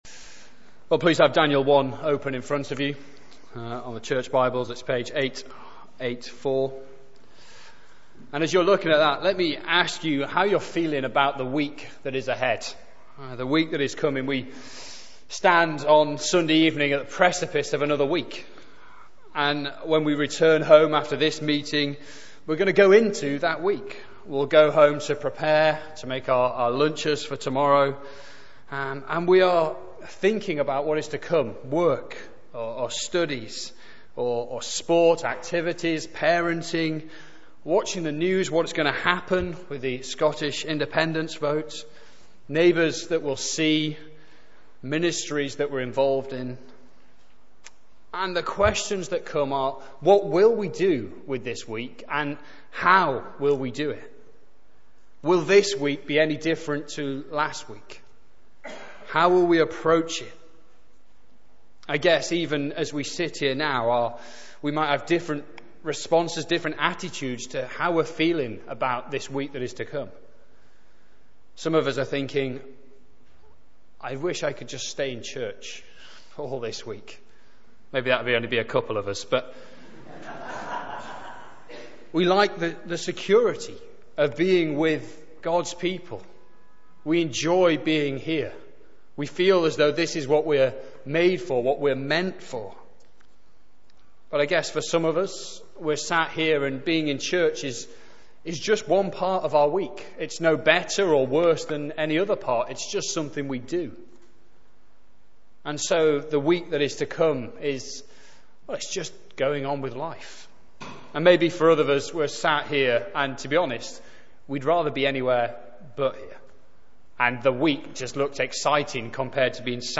Sermons - Kensington